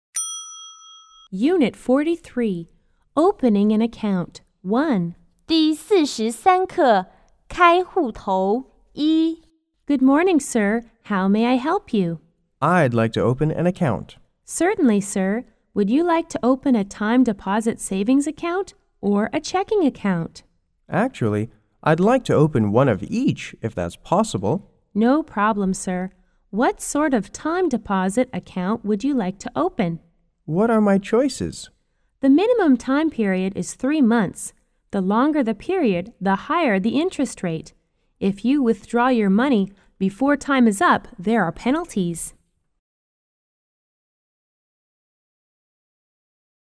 S= Salesperson S= Customer